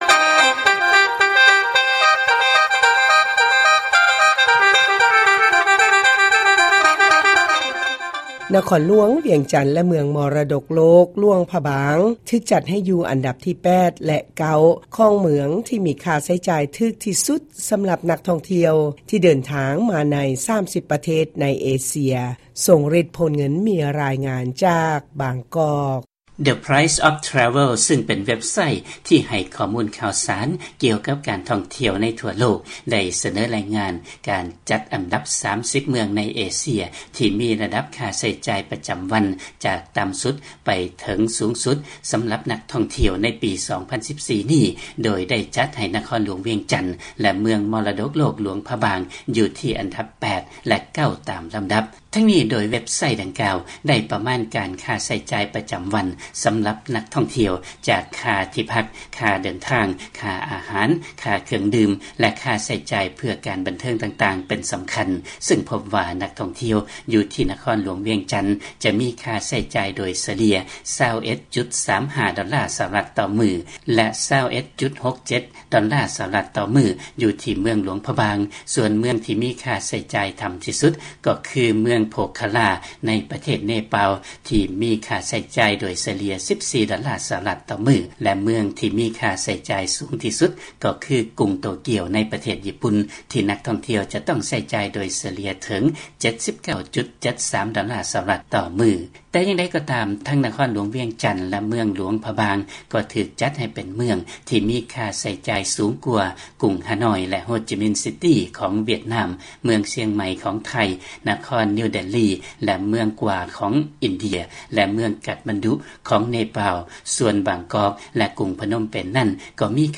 ຟັງລາຍງານ ວຽງຈັນ ແລະຫຼວງພະບາງ ຕິດອັນດັບ 8 ແລະ 9